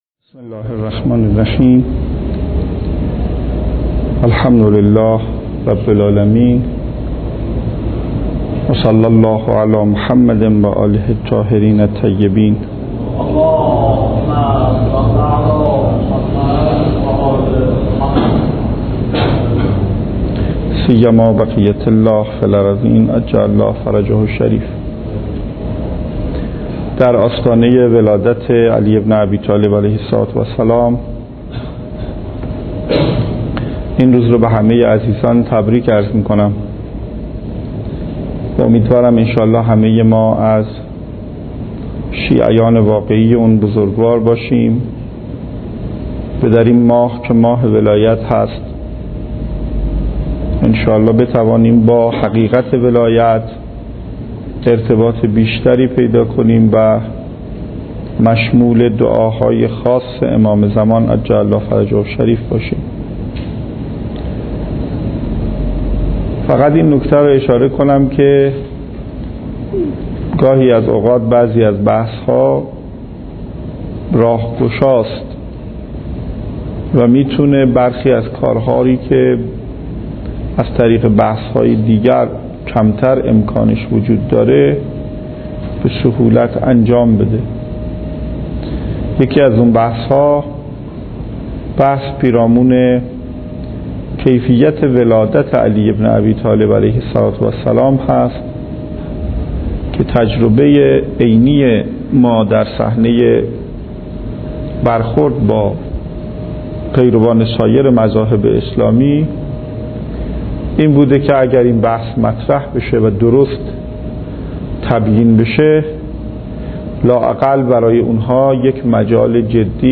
درس خارج اصول